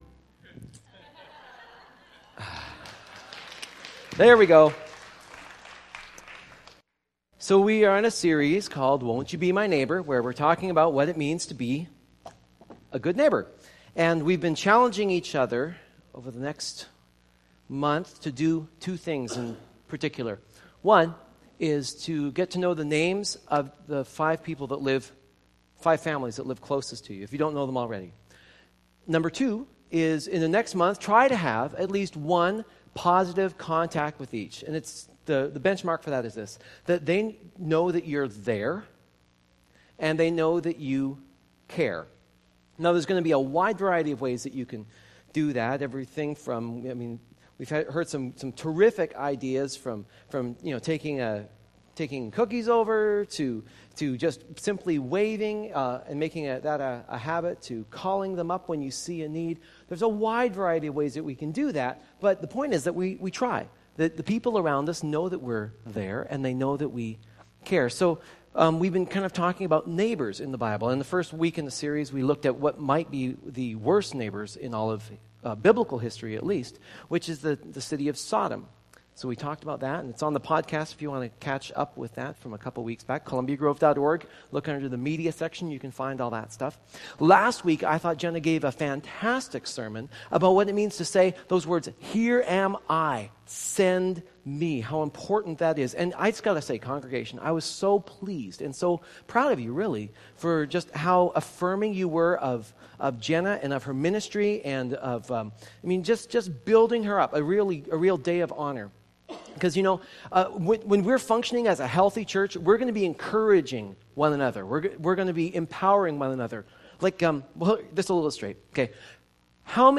Sermons from Columbia Grove Covenant Church in East Wenatchee WA.